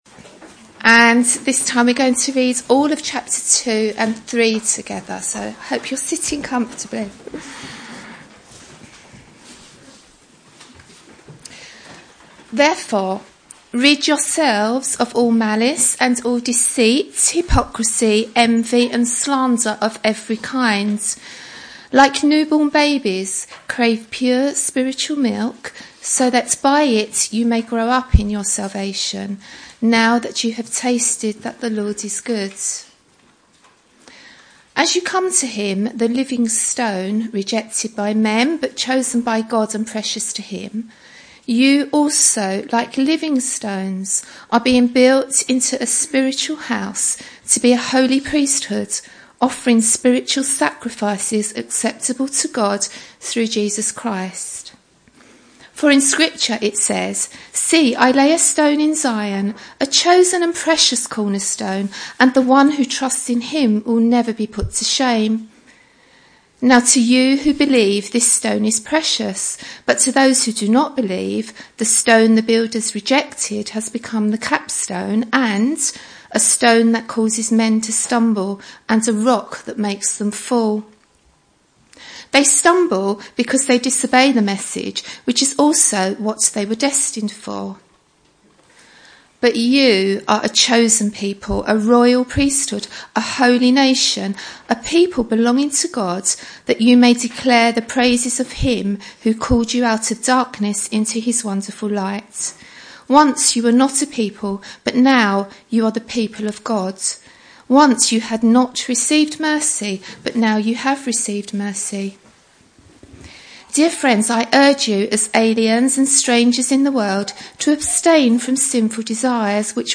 Session 2 of the GBWASE women's day conference - Taste & See'